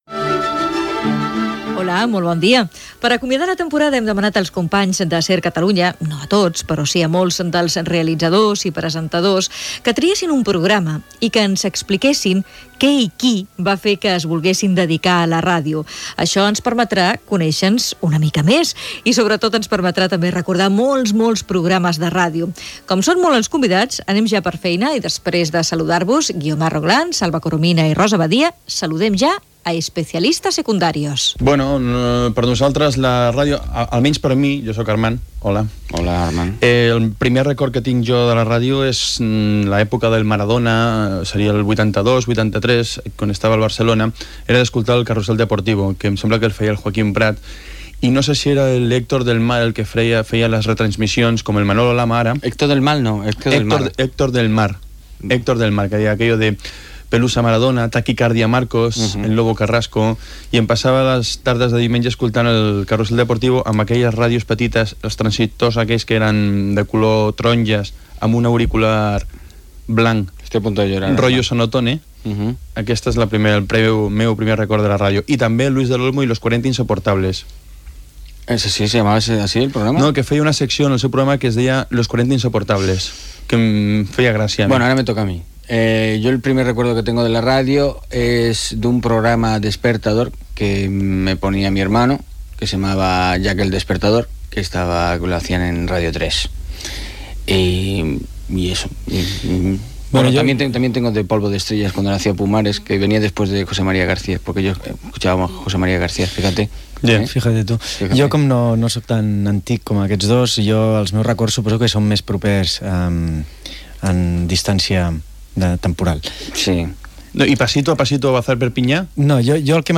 Fragment de Carrusel Deportivo. Crònica hípica d'Héctor del Mar.
Divulgació